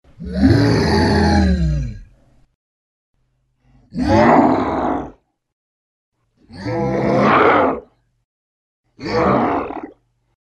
Звуки чудовища